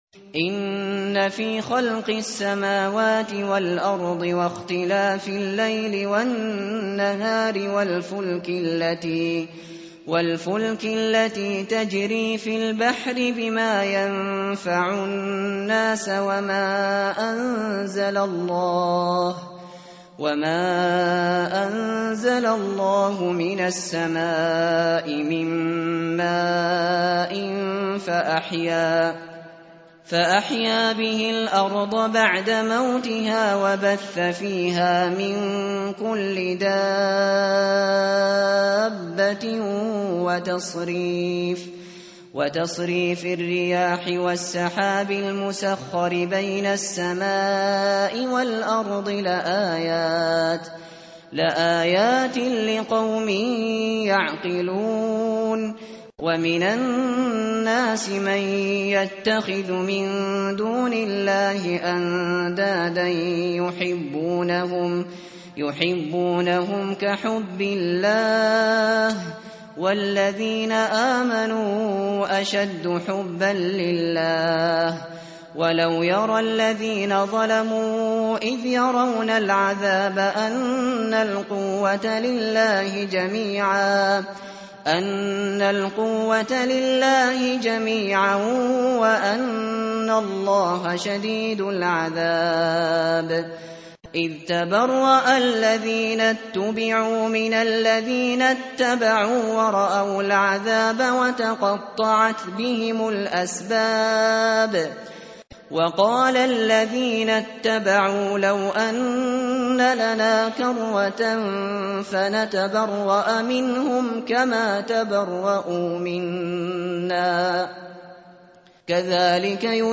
متن قرآن همراه باتلاوت قرآن و ترجمه
tartil_shateri_page_025.mp3